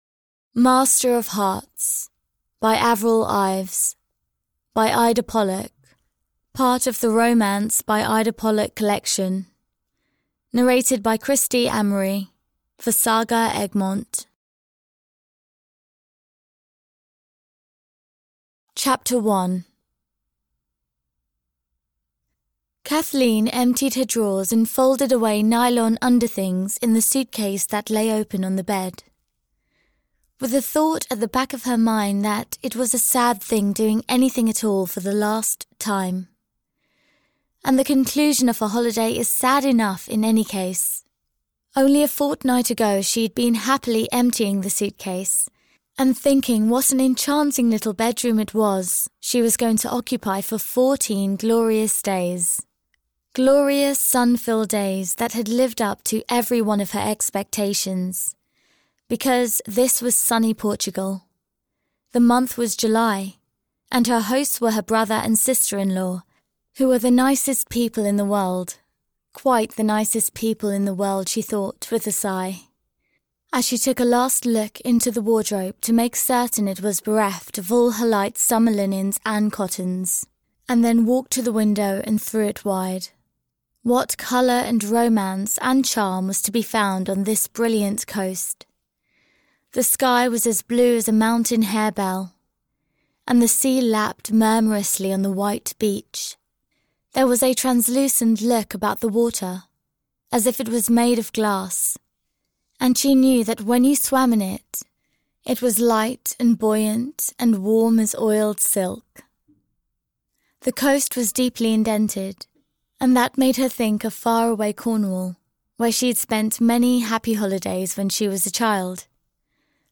Master of Hearts (EN) audiokniha
Ukázka z knihy